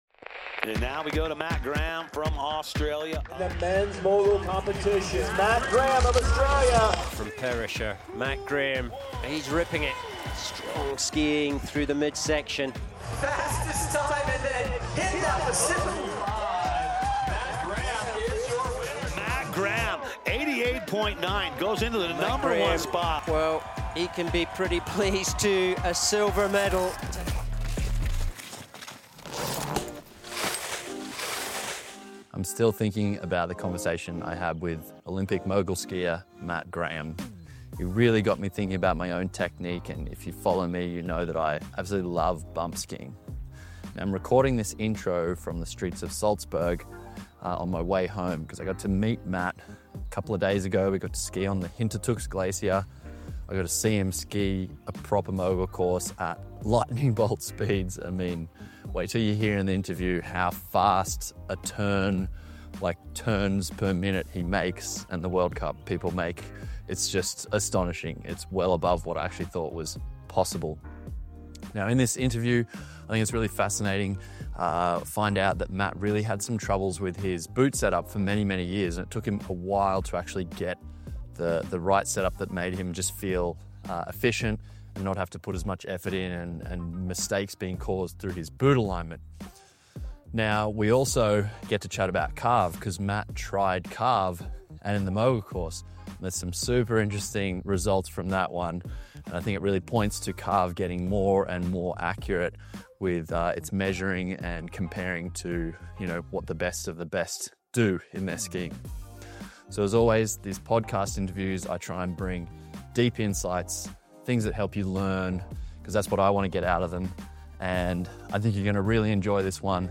I have to admit that one of my core beliefs about moguls was challenged during this most recent conversation with Olympic Moguls silver medalist Matt Graham.